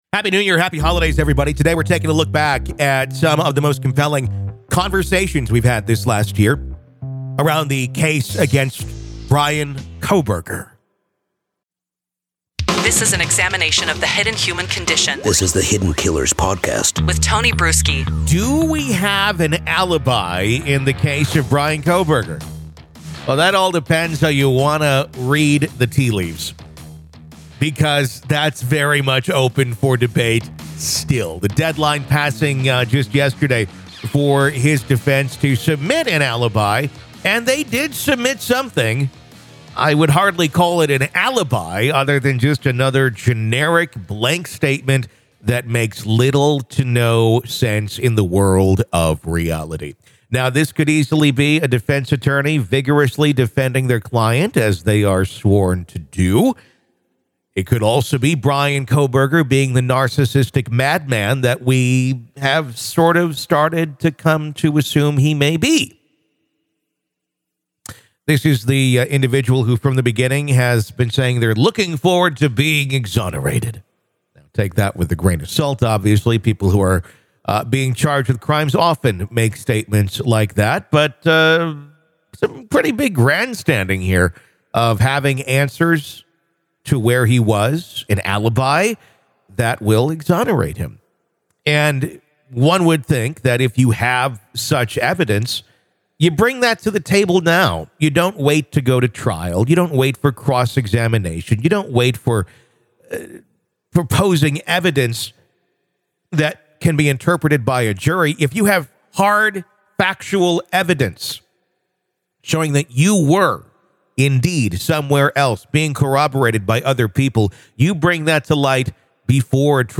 Hear the most riveting moments from the Idaho Murders Podcast, featuring in-depth discussions, riveting reporting, and the latest breaking updates on the case against Kohberger.